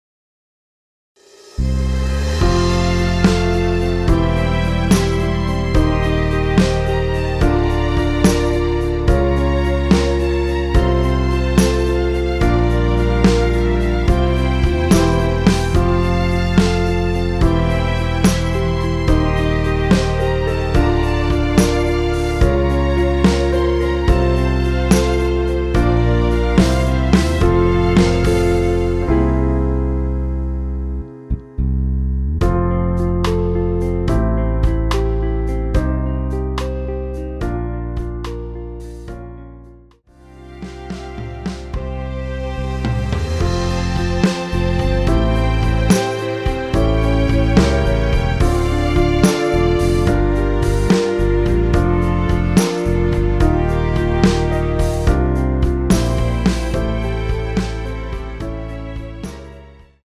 원키에서(+5)올린 MR입니다.
앞부분30초, 뒷부분30초씩 편집해서 올려 드리고 있습니다.